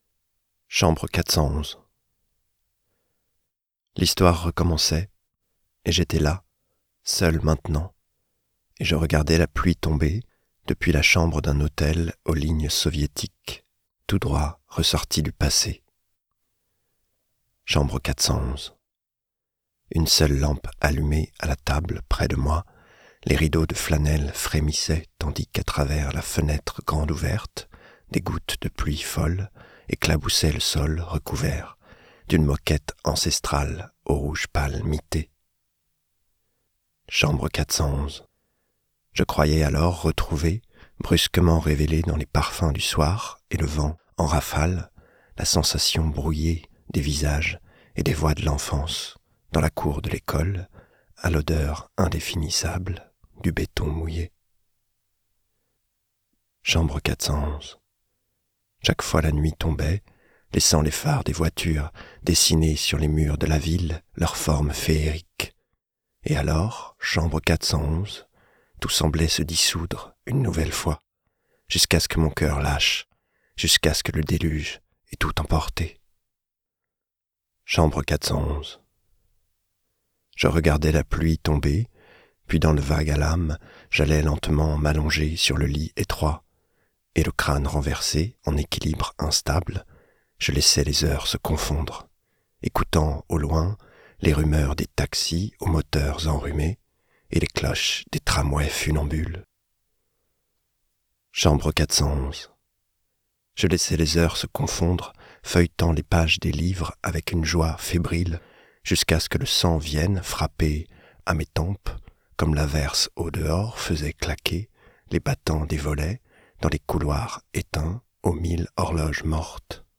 Extrait lu par l’auteur :